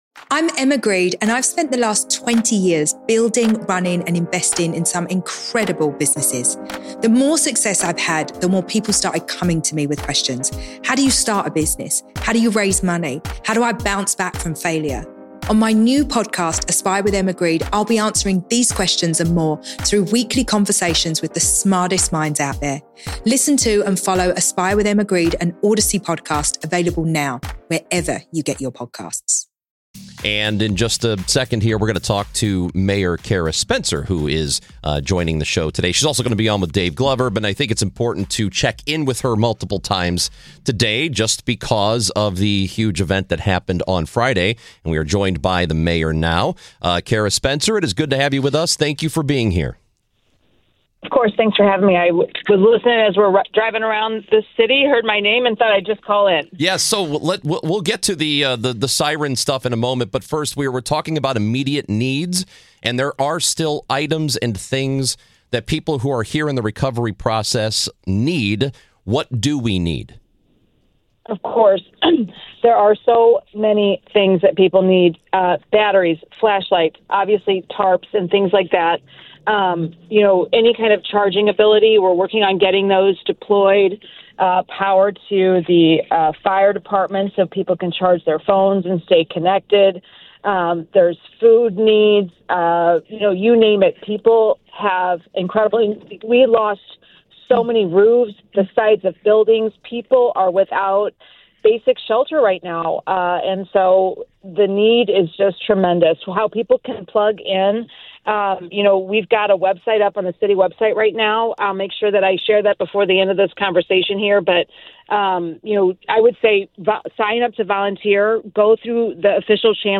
St Louis Mayor Cara Spencer joins at the top of the show with an update of what she's seen in the damaged areas following the tornado, 'the destruction is truly, truly phenomenal'. She says the Missouri National Guard is in the area doing an assessment of the situation.